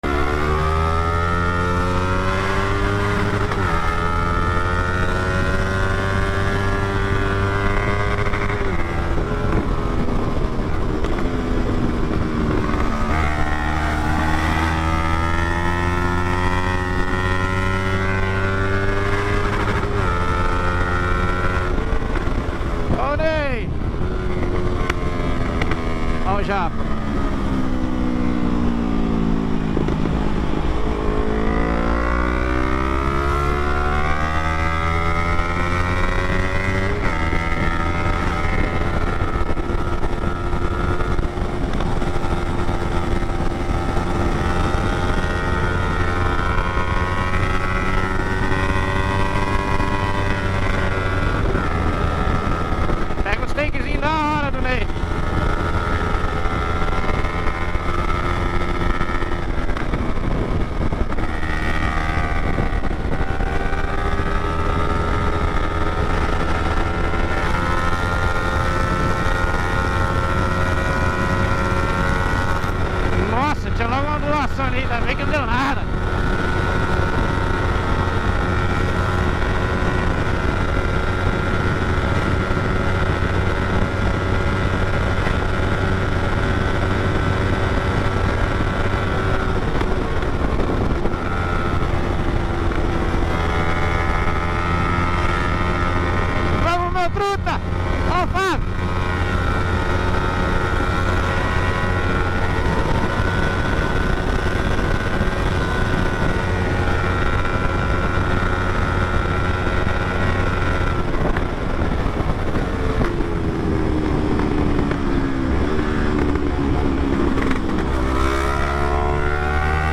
BMW1250 E MT03 Acelerando Na Sound Effects Free Download